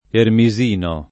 ermisino
ermisino [ ermi @& no ] o ermesino s. m.